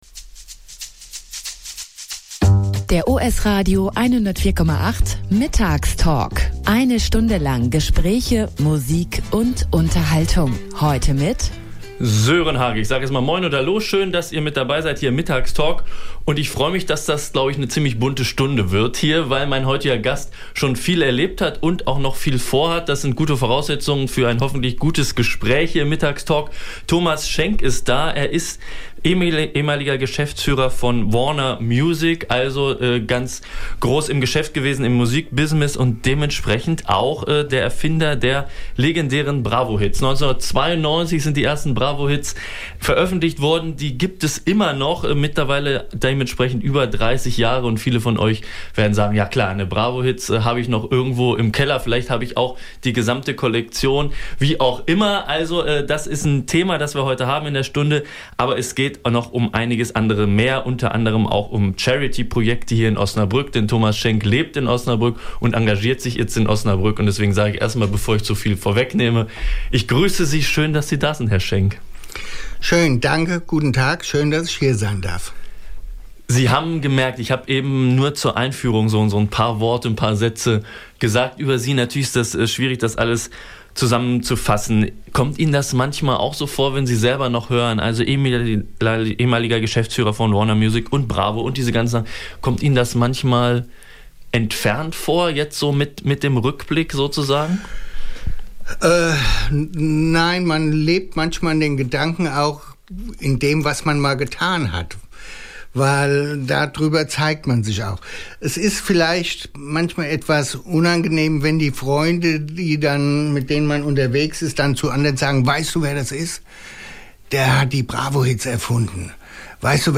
Mittags Talk